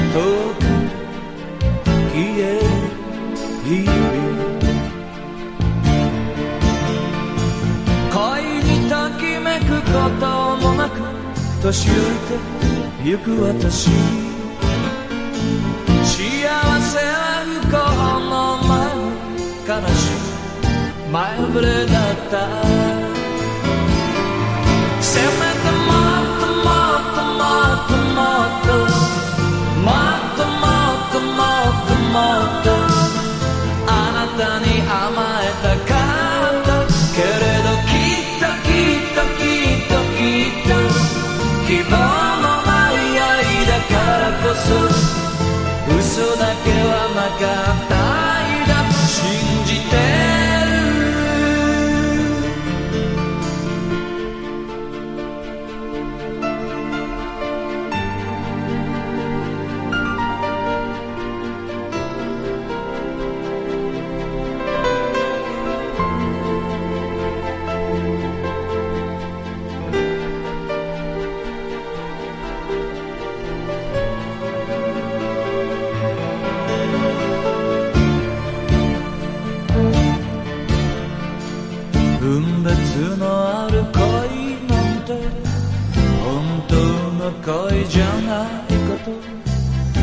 SSW / FOLK# POP# CITY POP / AOR